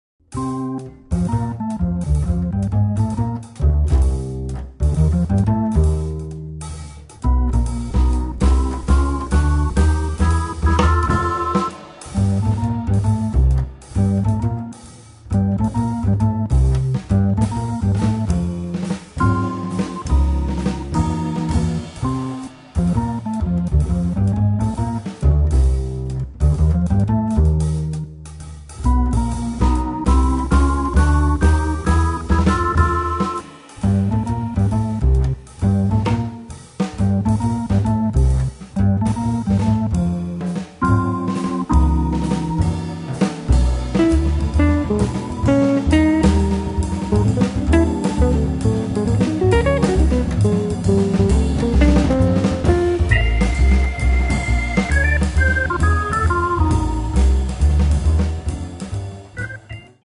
chitarra
organo e piano elettrico
contrabbasso
batteria